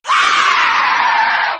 witch_dies.ogg